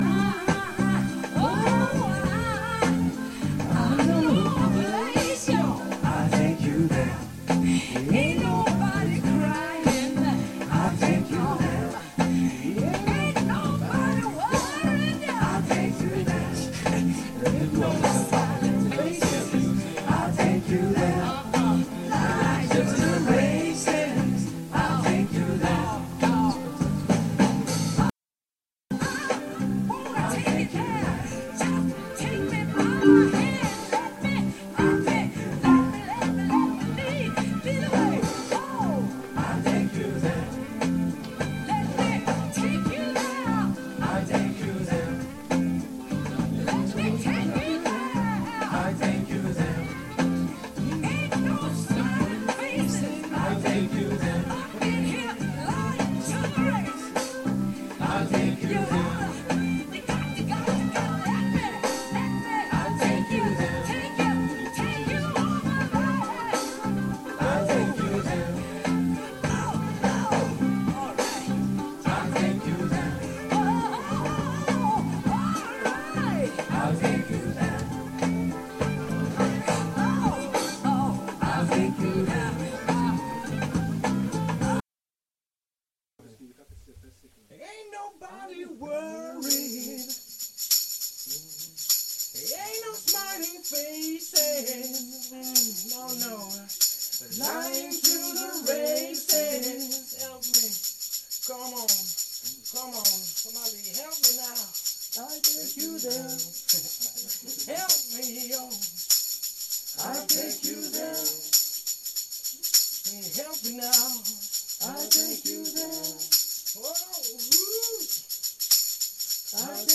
Choeurs pour travail
choeur_IllTakeYouThere.mp3